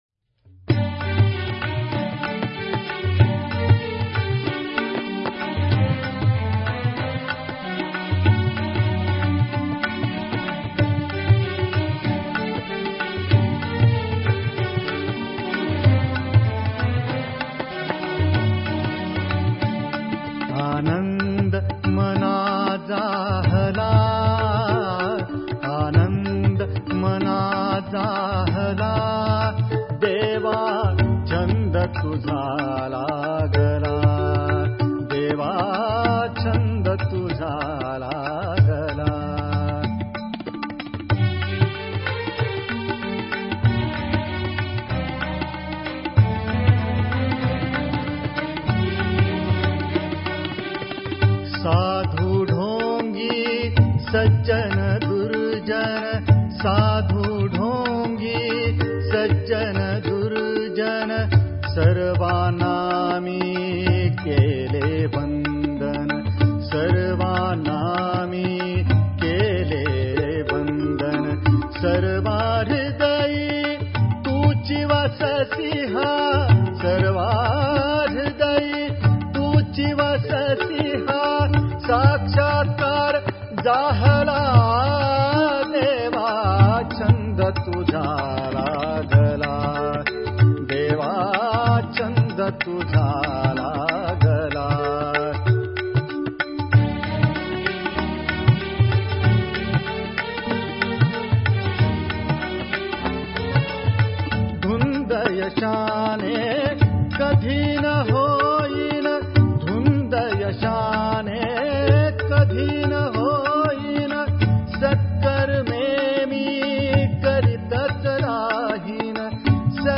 Devotional Hymns of Shree Shankar Maharaj